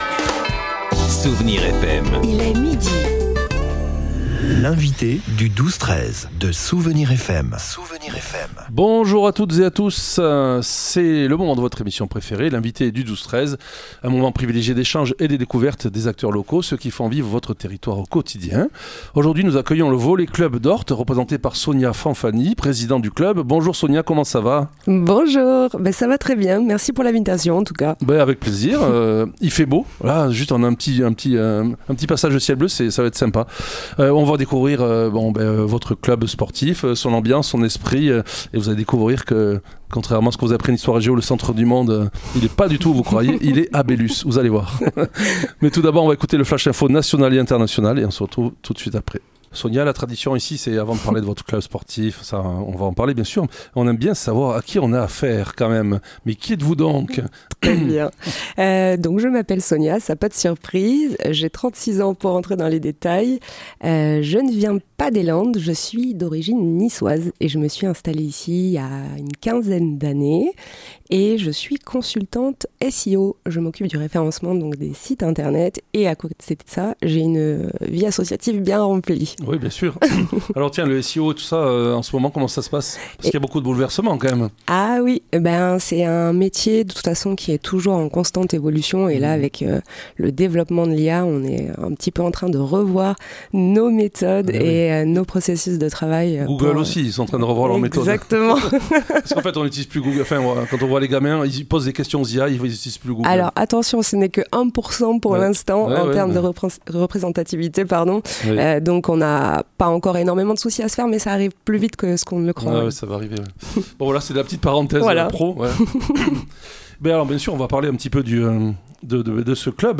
L'invité(e) du 12-13 de Soustons recevait aujourd'hui Le Volley Club d’Orthe